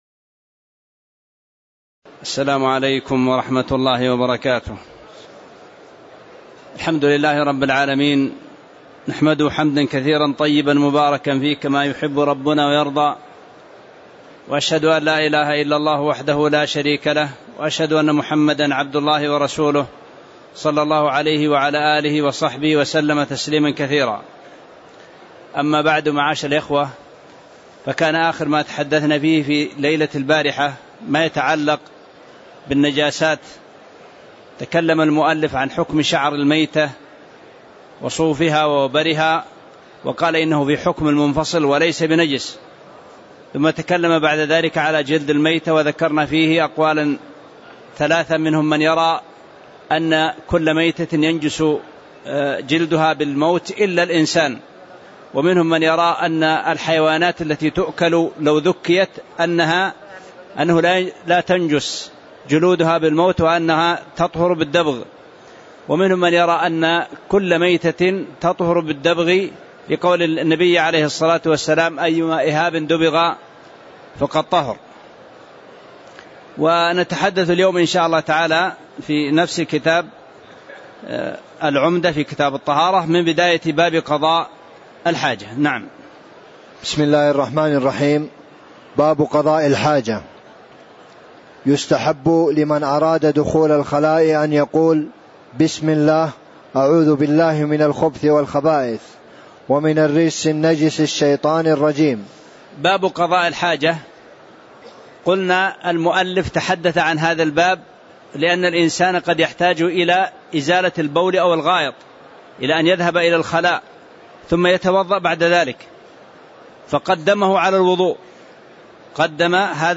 تاريخ النشر ٢٨ ذو الحجة ١٤٣٧ هـ المكان: المسجد النبوي الشيخ